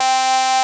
CATOLEADC4.wav